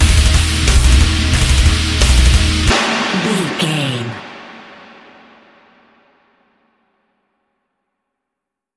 Aeolian/Minor
E♭
drums
electric guitar
bass guitar
hard rock
lead guitar
aggressive
energetic
intense
nu metal
alternative metal